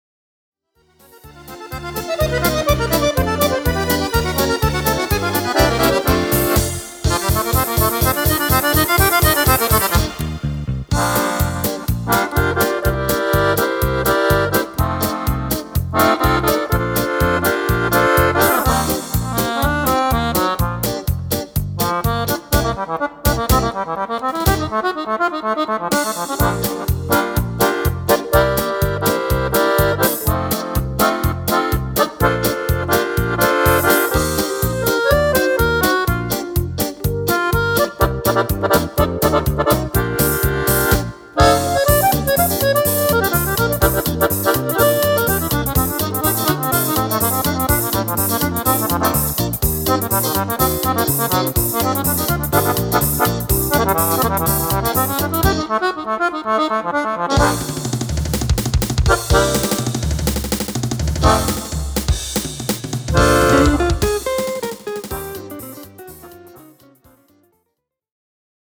Allegro jazz
Fisarmonica
Strumento Fisarmonica (e Orchestra)